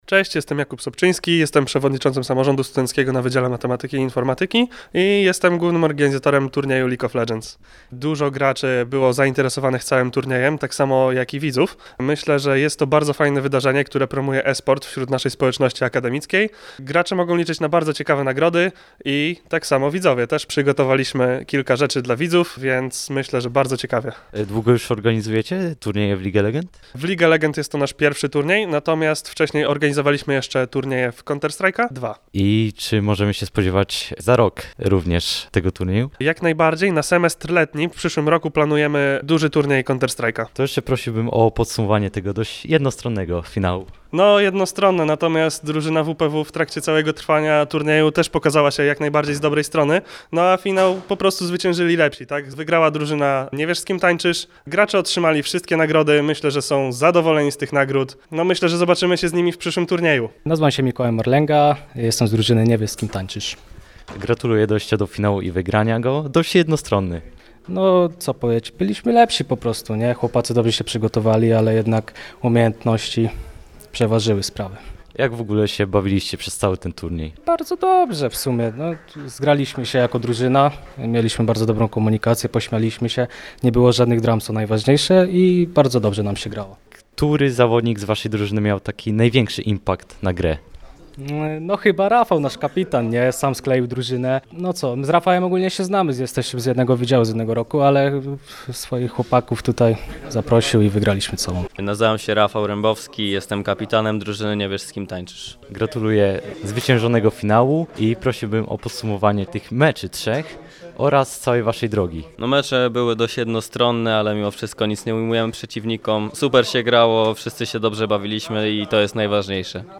Na UWM zakończył się turniej League of Legends - Radio UWM FM